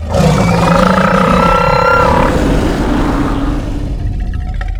sonic scream.wav